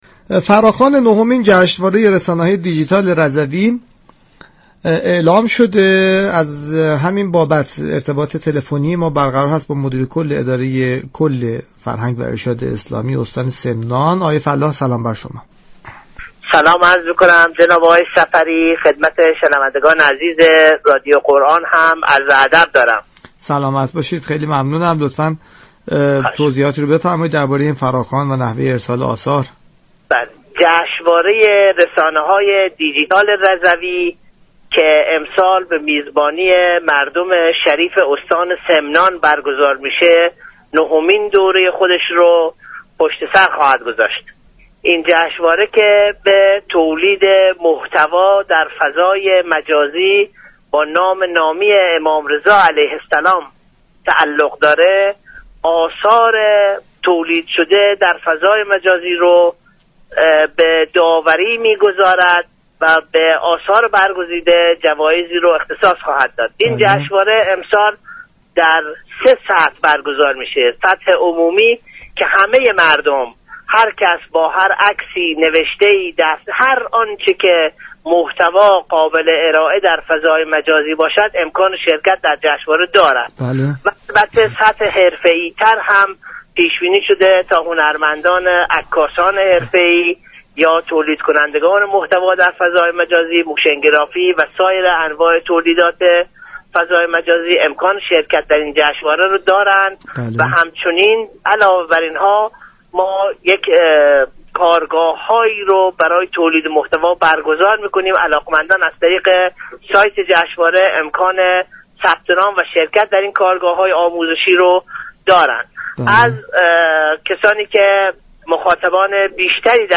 به گزارش پایگاه اطلاع رسانی رادیو قرآن؛ فرشید فلاح مدیركل فرهنگ و ارشاد اسلامی استان سمنان در گفتگو با برنامه والعصر رادیو قرآن از انتشار فراخوان نهمین جشنواره رسانه های دیجیتال رضوی (آهوان) خبر داد و گفت: اداره كل فرهنگ و ارشاد اسلامی استان سمنان با همكاری بنیاد بین المللی فرهنگی و هنری امام رضا (ع) و مركز توسعه فرهنگ و هنر در فضای مجازی ، نهمین جشنواره ملی رسانه های دیجیتال رضوی " آهوان" را با محوریت پویش تولید محتوای رضوی در فضای مجازی برگزار می نمایند.